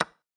capture.mp3